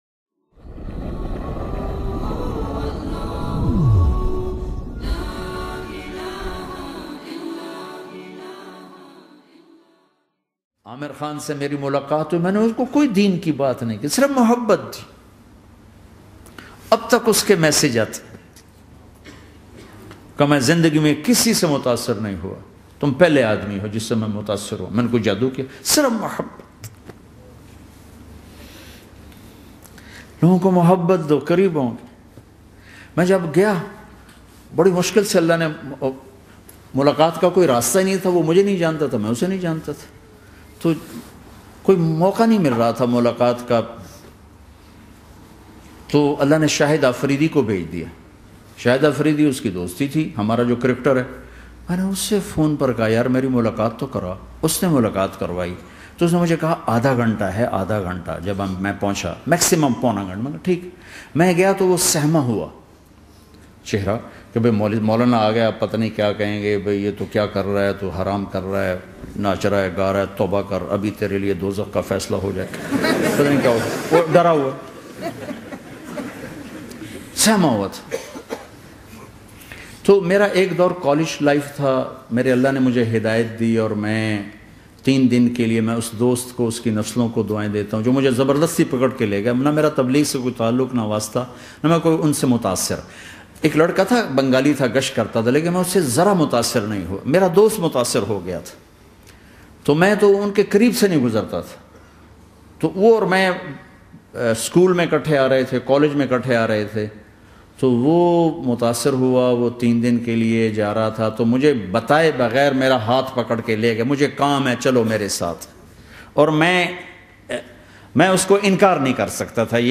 Giving Dawah to Aamir Khan By Maulana Tariq Jameel bayan mp3